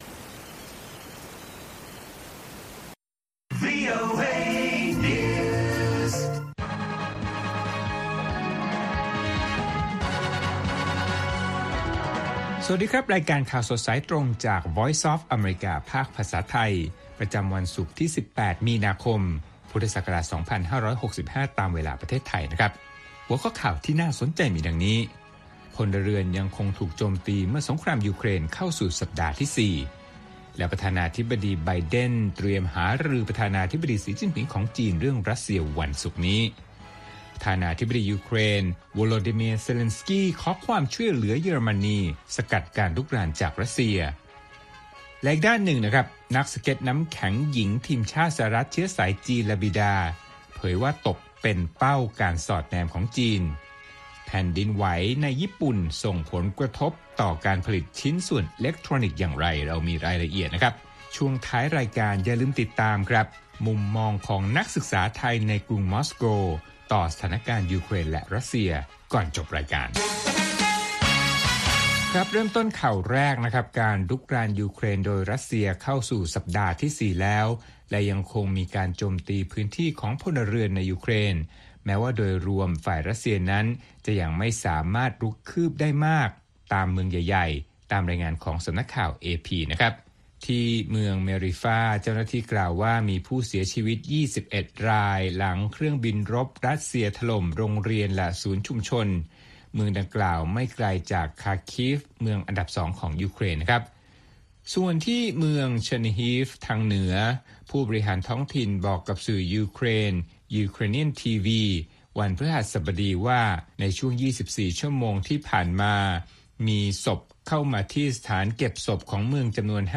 ข่าวสดสายตรงจากวีโอเอ ภาคภาษาไทย ประจำวันศุกร์ที่ 18 มีนาคม 2565 ตามเวลาประเทศไทย